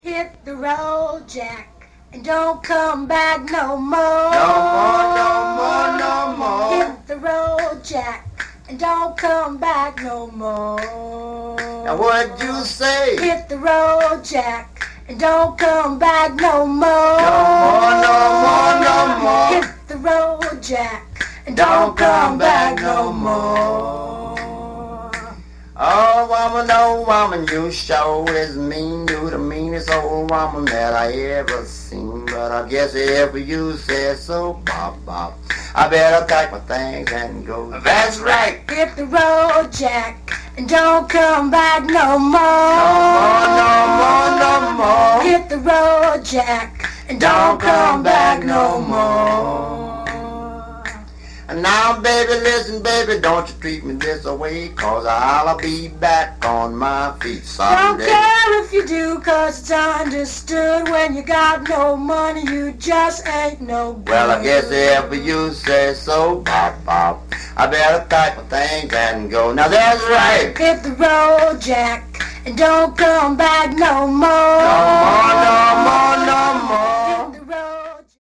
original accapella version